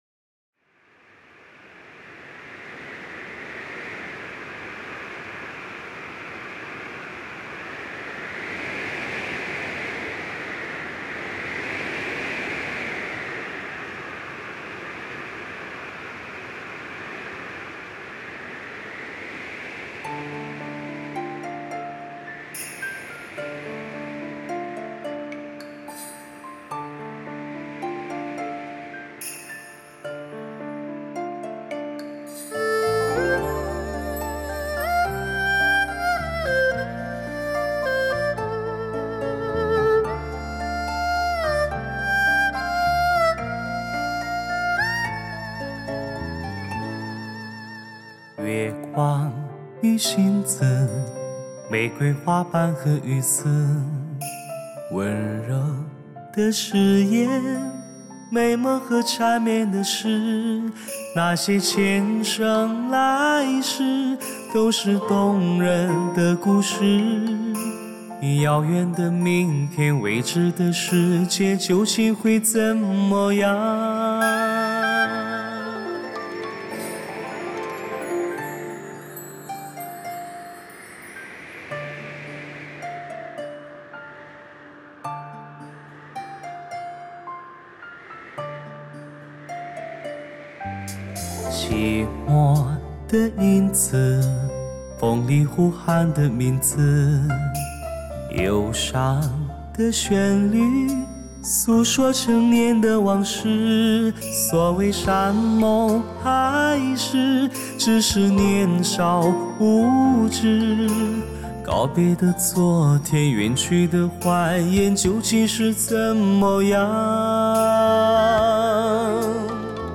DTS-ES6.1
空气感和层次感兼顾，细节还原和定位感全面提升，乐器人声在各个声道的渗透诠释，